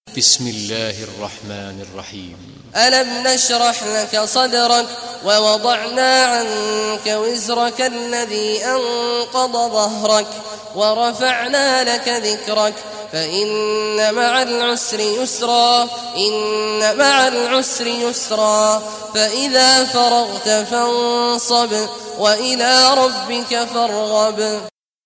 Surah Ash-Sharh Recitation by Sheikh Al Juhany
Surah Ash-Sharh, listen or play online mp3 tilawat / recitation in Arabic in the beautiful voice of Sheikh Abdullah Awad Al Juhany.